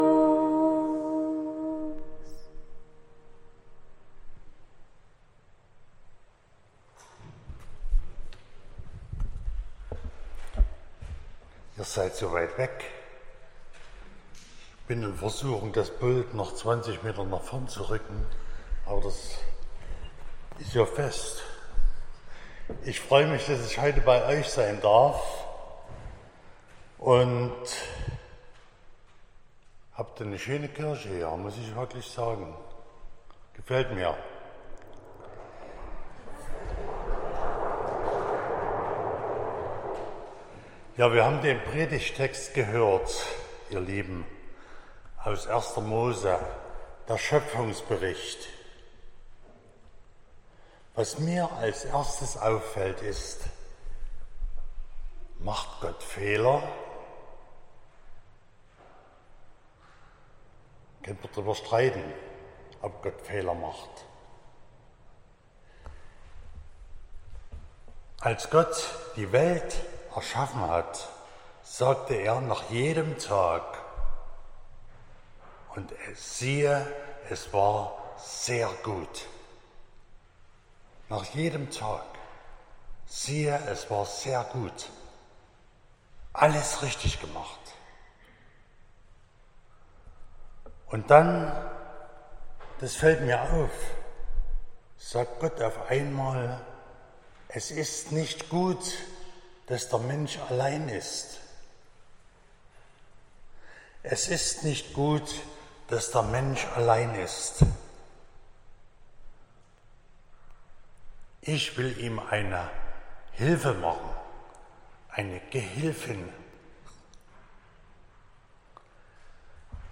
13.10.2024 – Gottesdienst
Predigt (Audio): 2024-10-13_Gemeinsam_geht_es_besser.mp3 (13,8 MB)